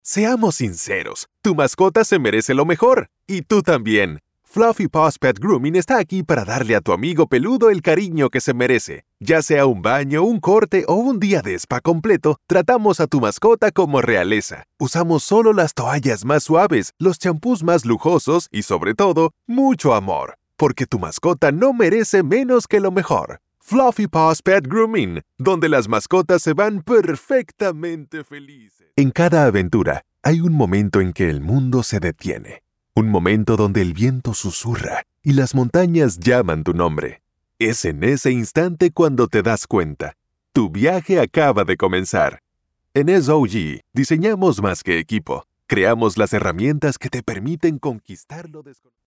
Male
Dominican Spanish (Native) Latin English (Accent) Neutral Latam Spanish (Native)
Deep.mp3
Acoustically treated studio.